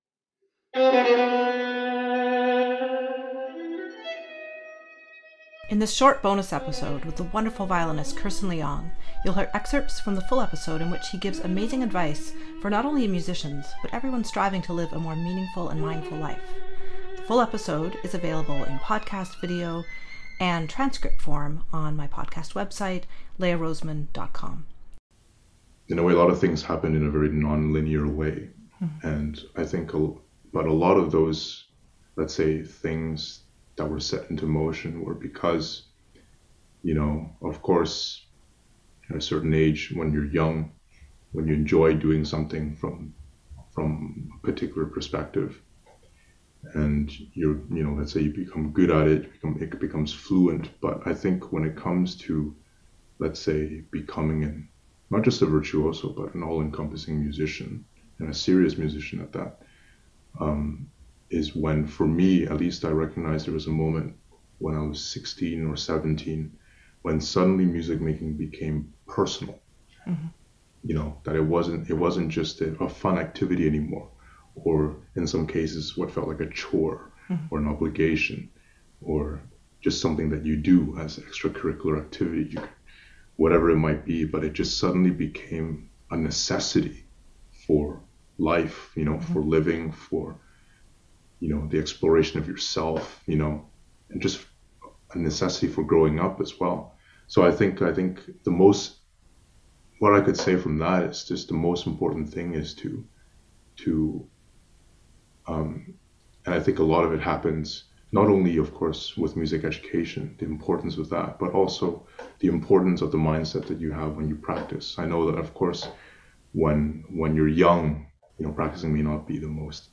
This conversation was recorded June 15, 2021.